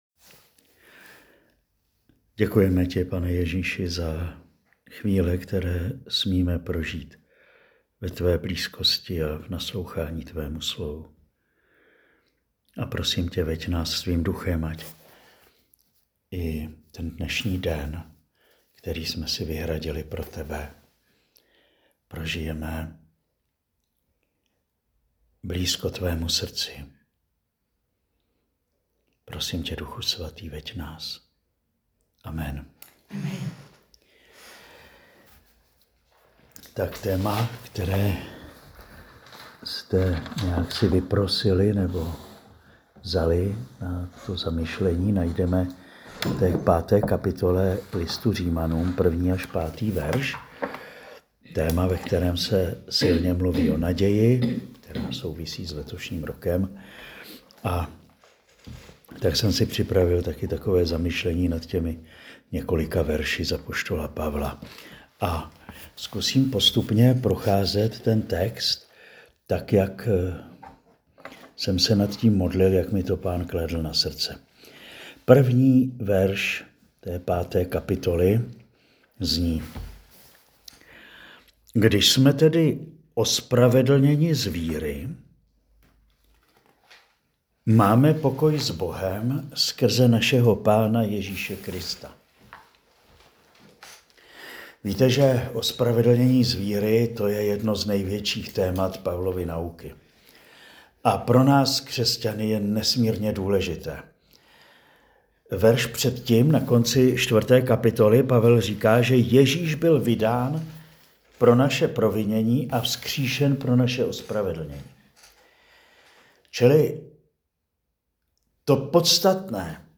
Promluva pro sestry salesiánky v Hradci Králové na text Řím 5,1-5.